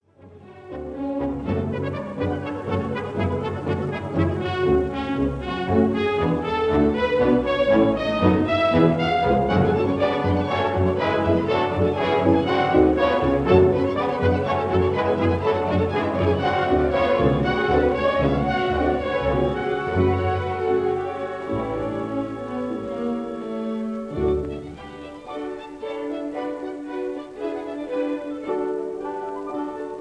This is a 1939 recording